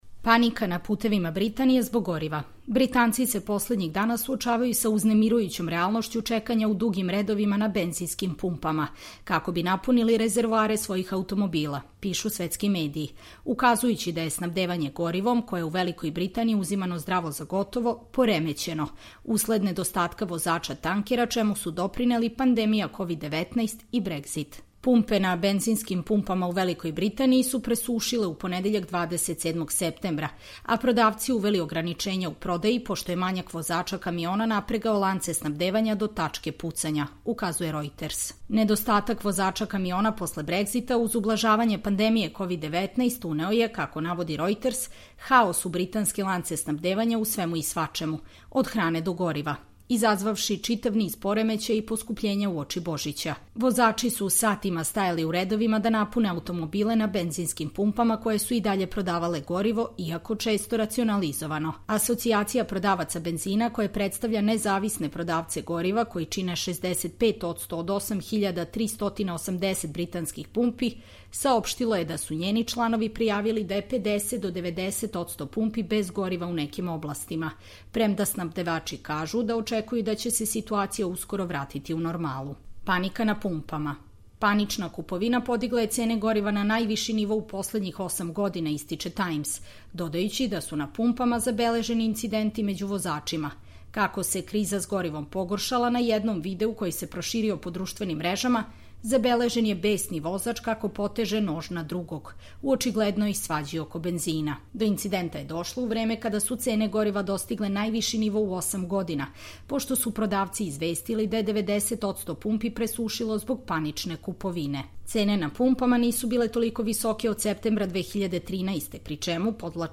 Čitamo vam: Panika na putevima Britanije zbog goriva